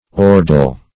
Meaning of ordal. ordal synonyms, pronunciation, spelling and more from Free Dictionary.